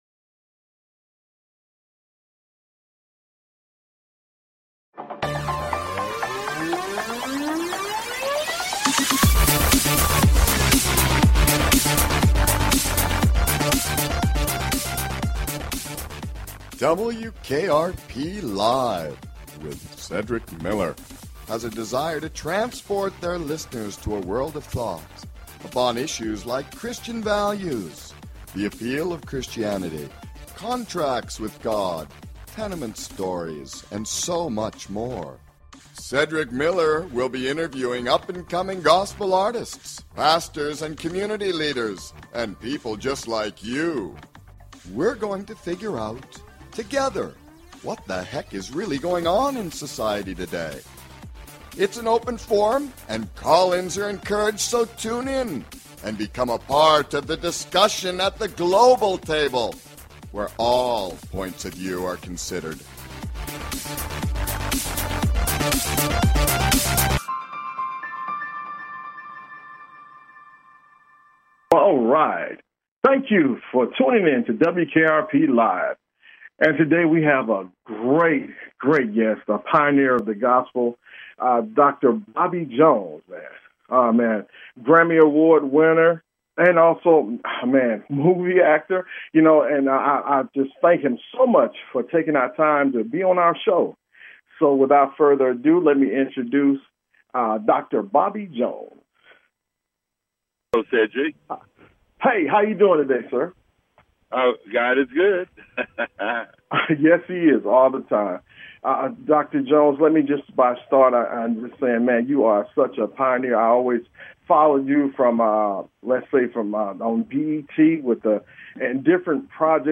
Talk Show Episode, Audio Podcast, WKRP Live and Guest, Singer Dr Bobby Jones on , show guests , about Bobby Jones, categorized as Arts,Music,Christianity
Guest, Singer Dr Bobby Jones